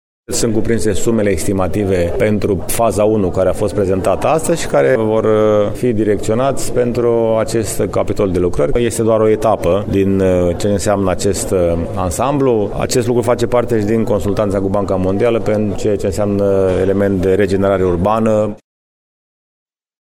George Scripcaru, primar municipiul Brașov.